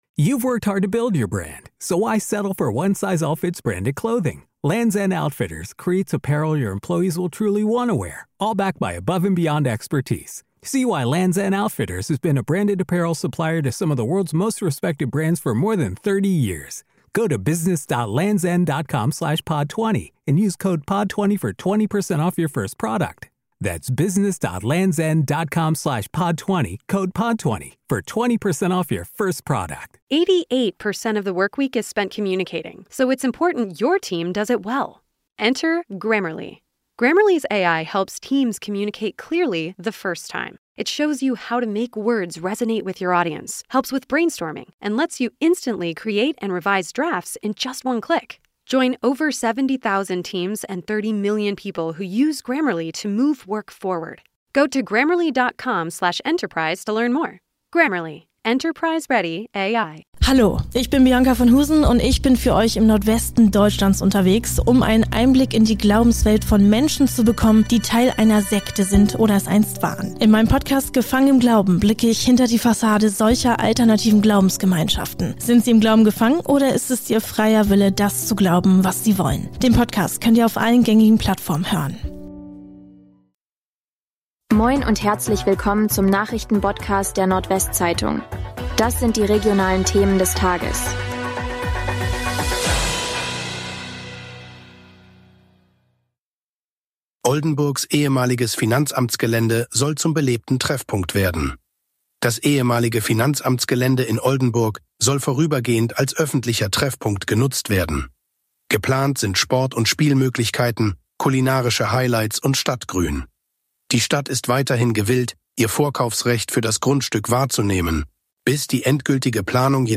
NWZ Nachrichten Botcast – der tägliche News-Podcast aus dem Norden